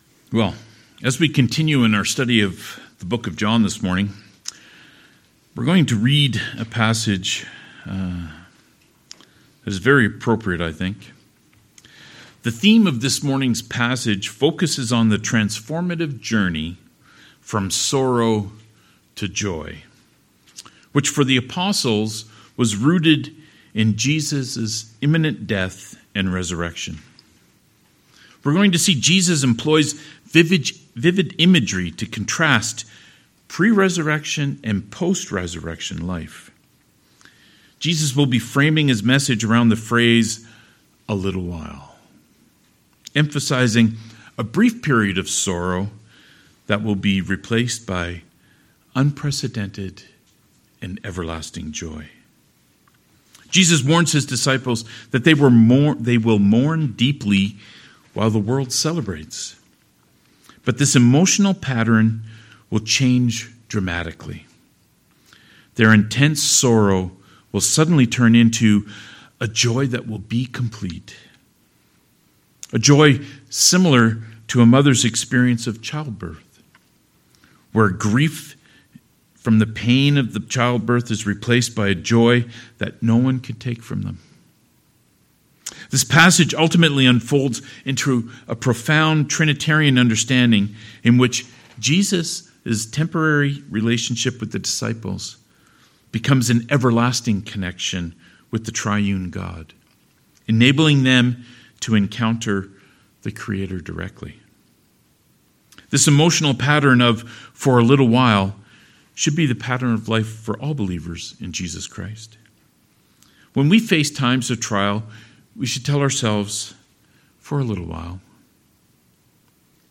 Passage: John 16: 16-24 Service Type: Sermons